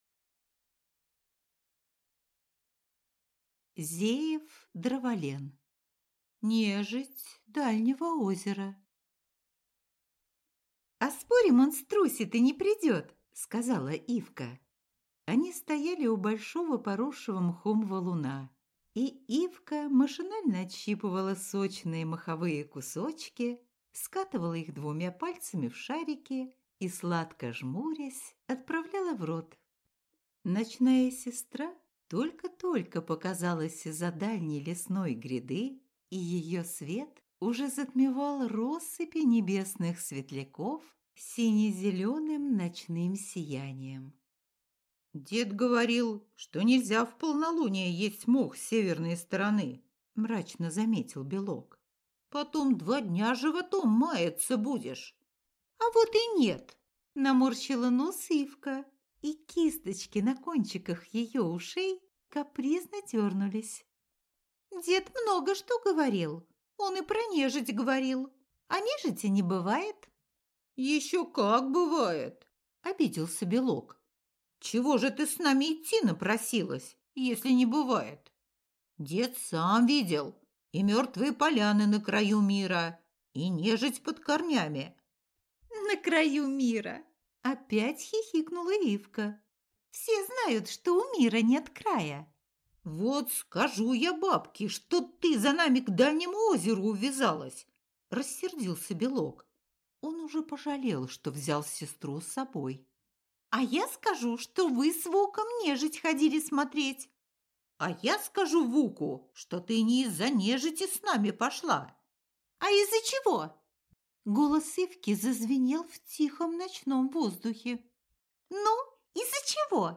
Аудиокнига Нежить Дальнего озера | Библиотека аудиокниг